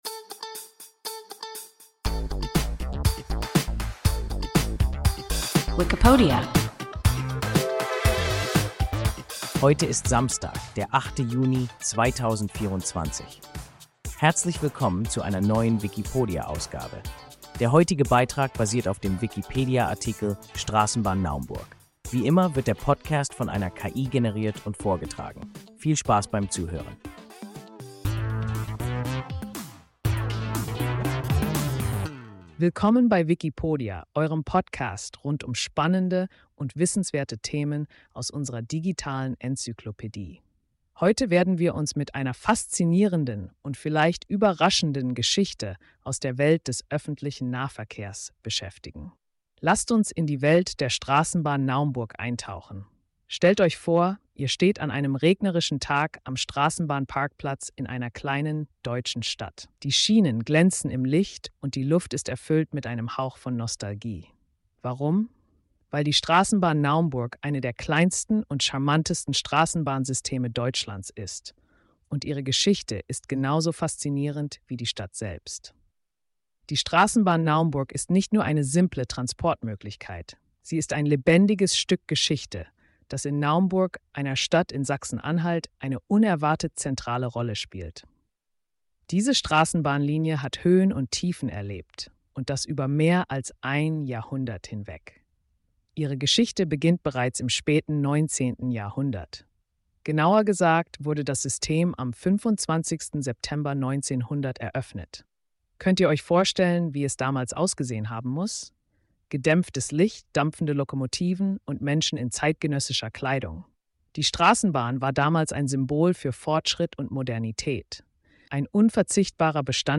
Straßenbahn Naumburg – WIKIPODIA – ein KI Podcast